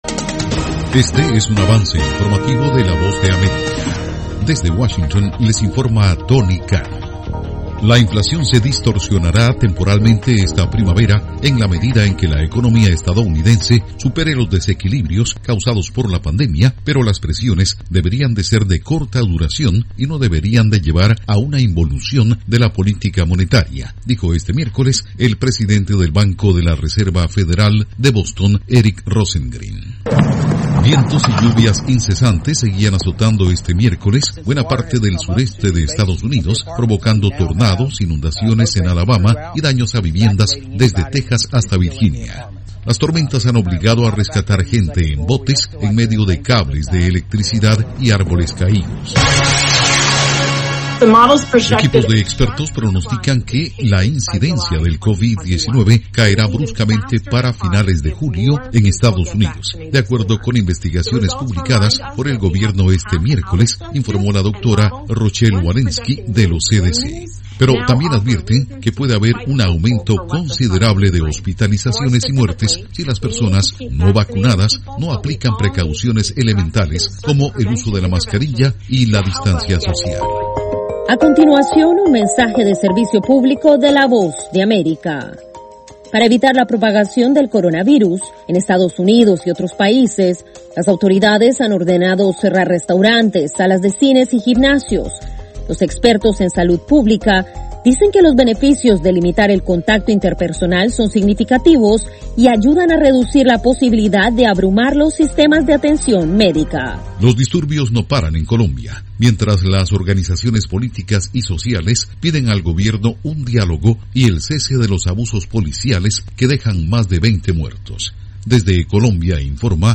Cápsula informativa de tres minutos con el acontecer noticioso de Estados Unidos y el mundo